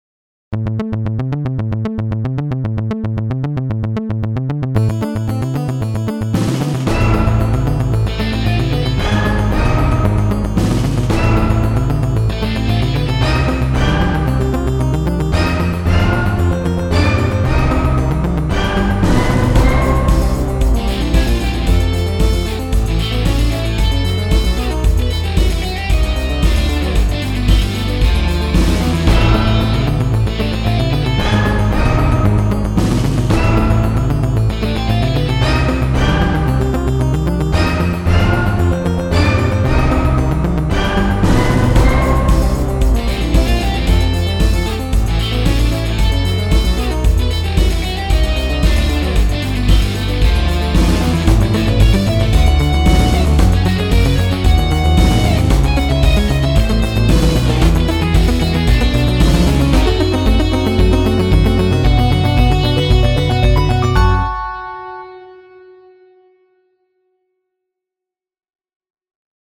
Пример инструментальной композиции (без вокала), в которой можно услышать, как сочно и плотно звучит современный синтезированный звук.
Studio_Bashnya_primer_zapisi_Sueta_instrumental.mp3